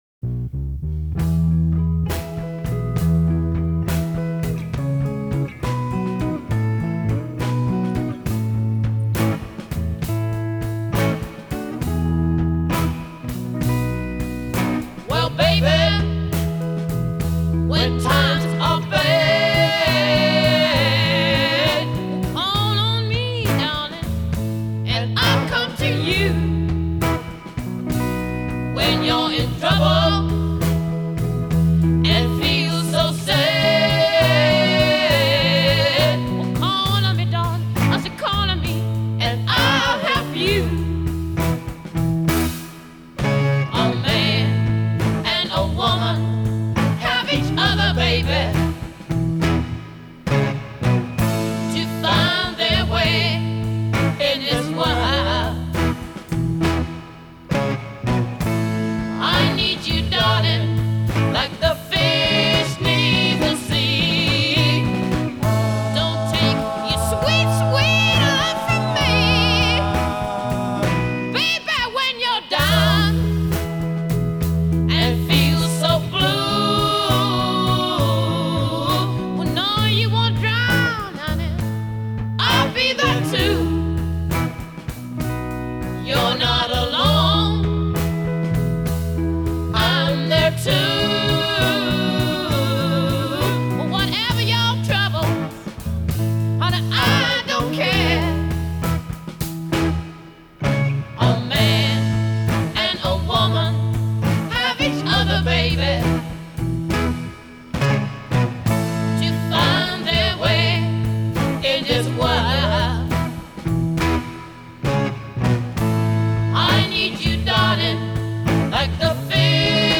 کلاسیک راک